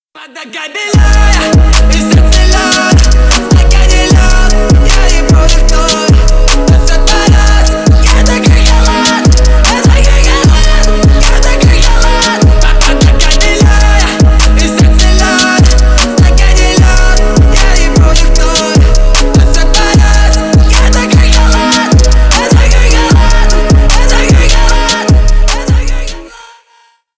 Ремикс
громкие